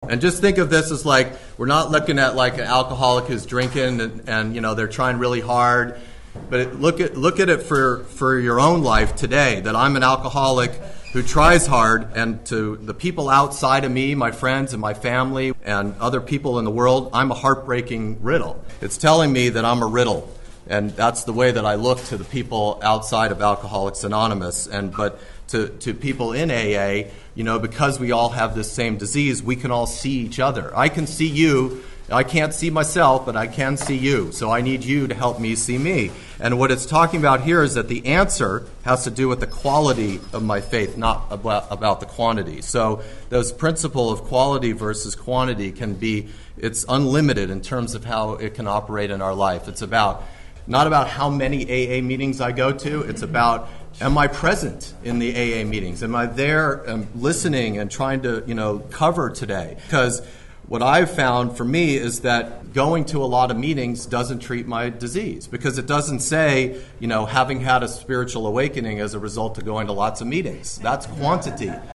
This audio archive is a compilation of many years of lecturing.
Within the lectures, you will hear people ask questions about why am I where I am, how can I get to a better place and what is blocking me.
The group shares how addictionism continues to manifest even after the obsession to drink or use is lifted, through restless thinking, self-will, and emotional imbalance.